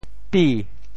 俾 部首拼音 部首 亻 总笔划 10 部外笔划 8 普通话 bǐ 潮州发音 潮州 bi2 文 中文解释 俾 <名> (形声。